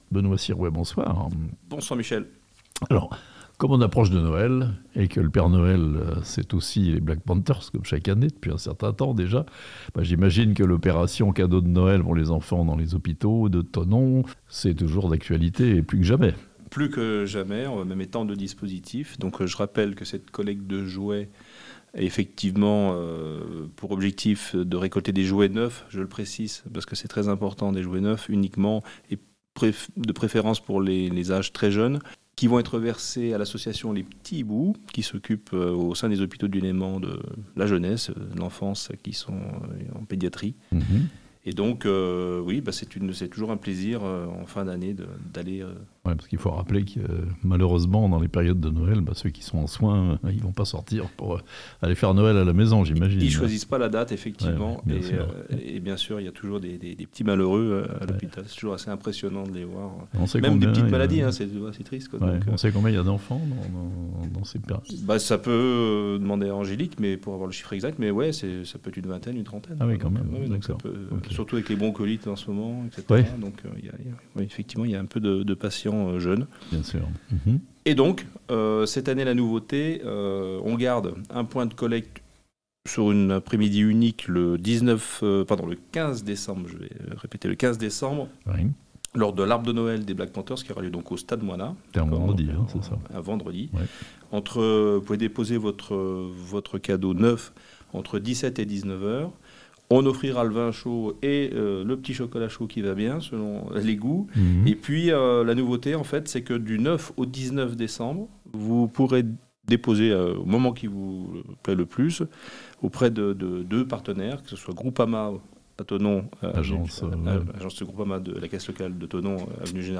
Des cadeaux pour les enfants hospitalisés grâce à l'équipe des Black Panthers de Thonon (interview)